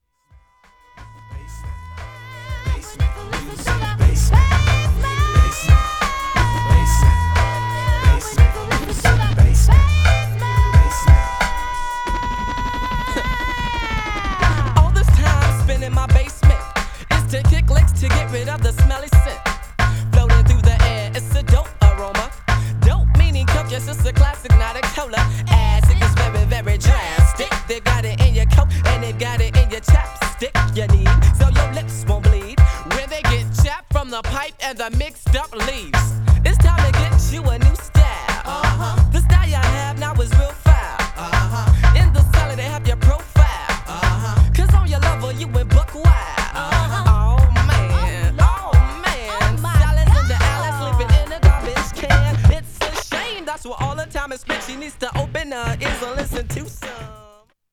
Styl: Hip Hop